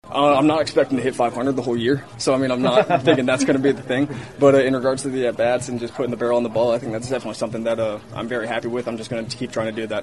Chavis is 8-of-16 with a home run and 6 RBIs this season.  He says he’s seeing the ball well right now.